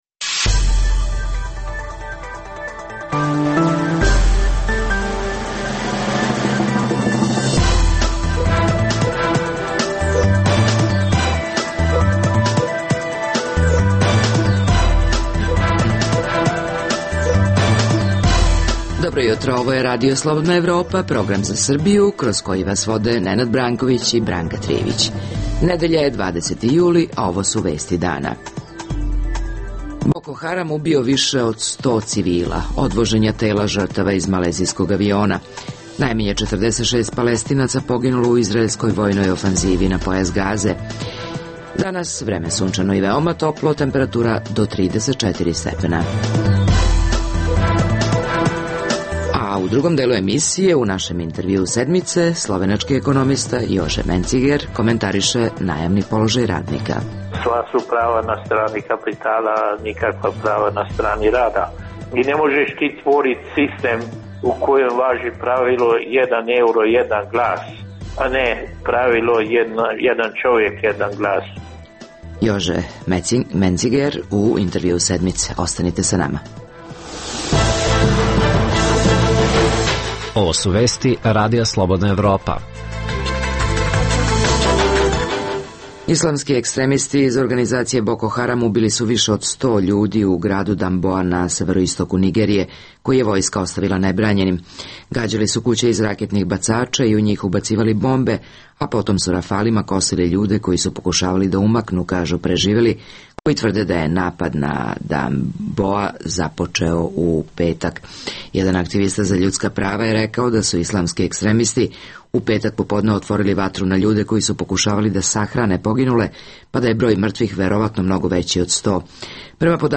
Emisija namenjena slušaocima u Srbiji koja sadrži lokalne, regionalne i vesti iz sveta te tematske priloge o aktuelnim dešavanjima priče iz svakodnevnog života.